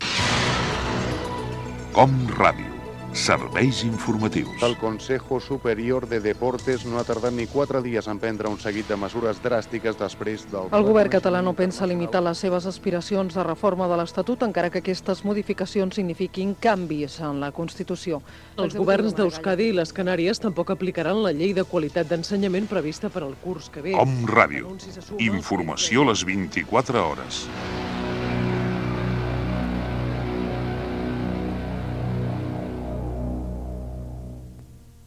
Promoció dels programes informatius de l'emissora